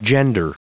Prononciation du mot gender en anglais (fichier audio)
Prononciation du mot : gender